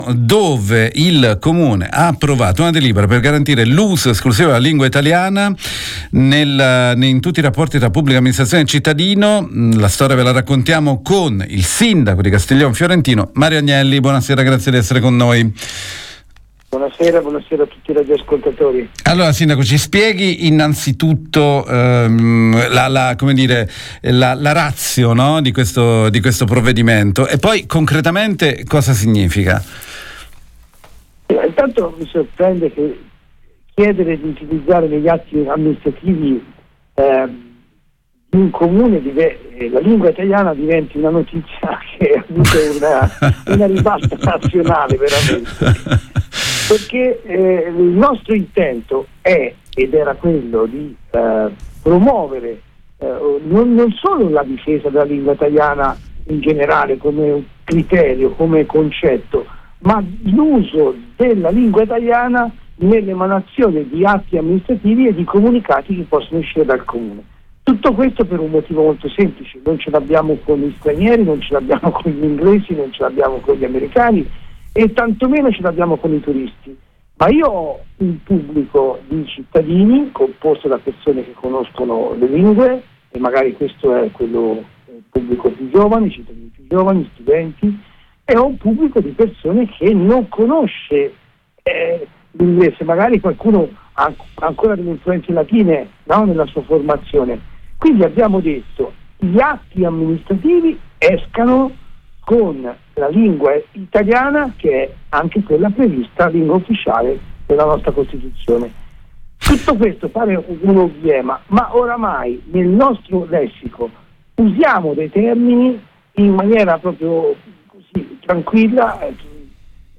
La giunta comunale di Castiglion Fiorentino (Arezzo) ha firmato una delibera per garantire l’uso esclusivo della lingua italiana “lingua ufficiale della Repubblica e prezioso patrimonio identitario della Nazione” in tutti i rapporti tra pubblica amministrazione e cittadino. Intervista con il sindaco di Castiglion Fiorentino, Mario Agnelli